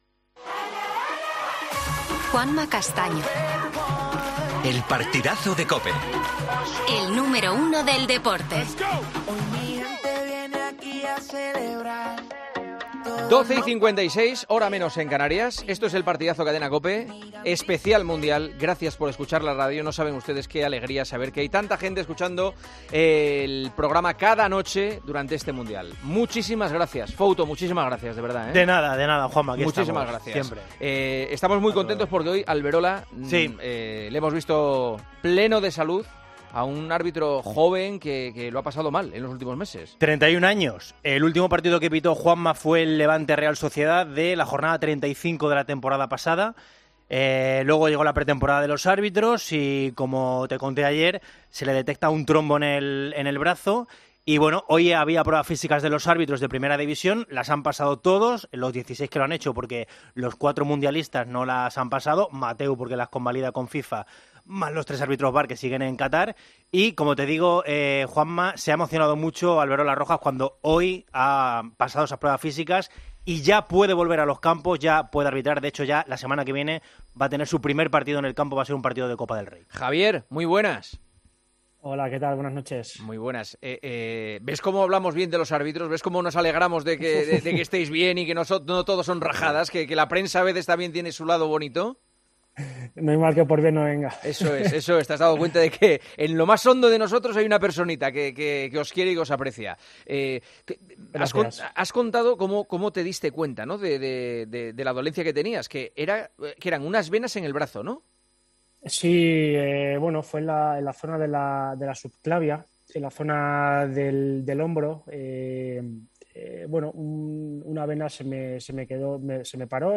Juanma Castaño habla con el árbitro tras haberse recuperado de la operación a la que fue sometido tras la obstrucción de dos venas en la zona de la clavícula.